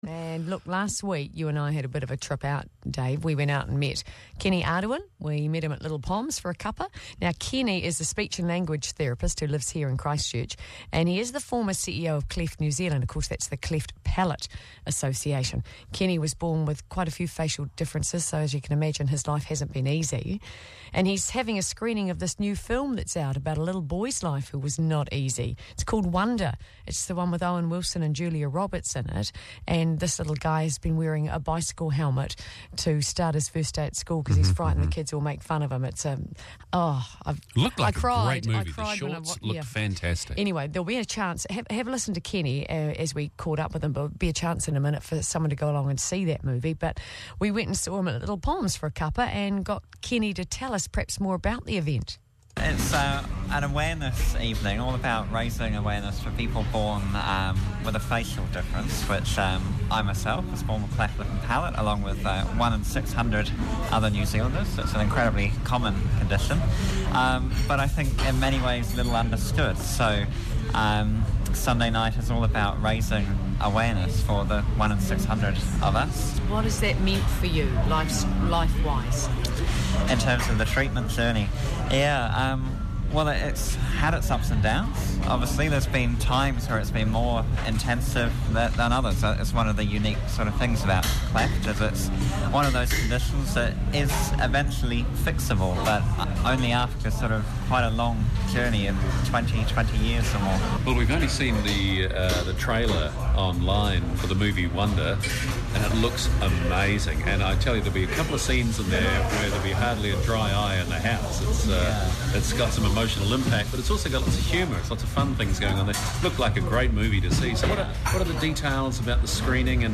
Interview on The Breeze Christchurch, 28 Nov 2017